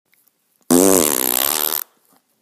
Play, download and share FartToungue original sound button!!!!
farttoungue.mp3